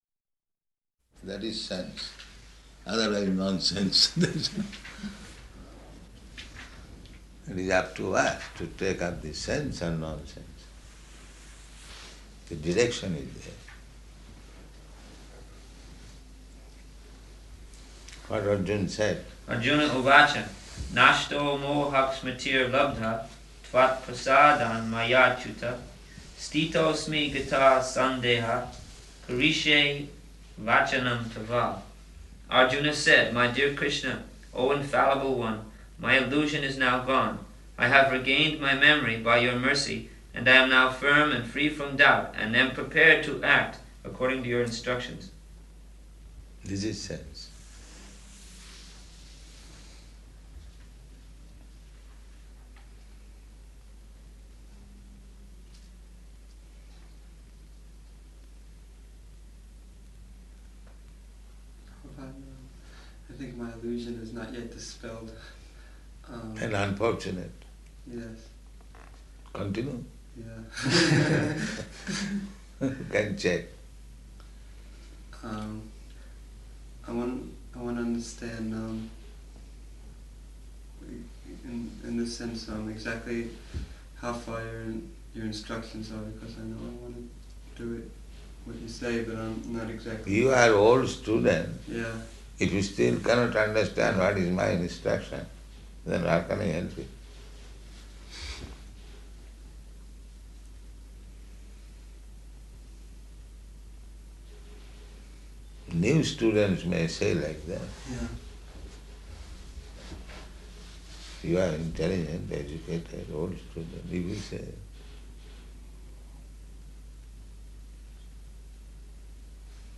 -- Type: Conversation Dated: July 1st 1974 Location: Melbourne Audio file